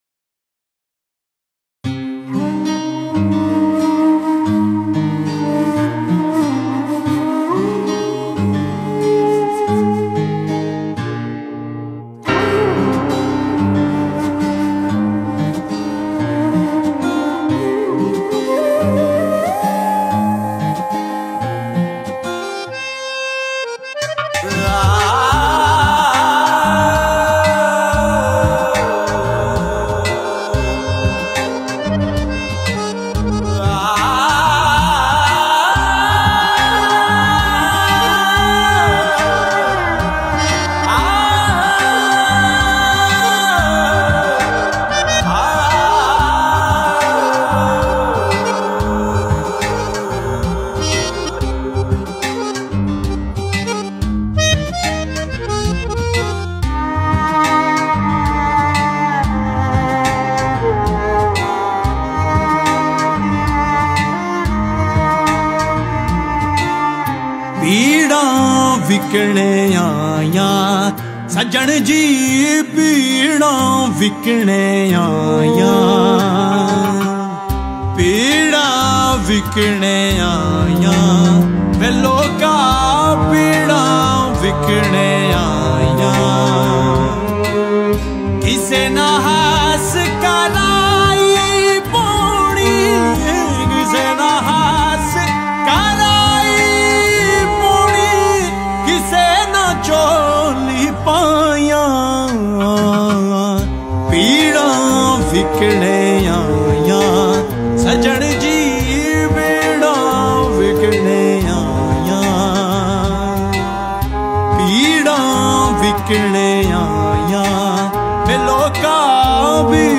Sufi Songs
Punjabi Kalam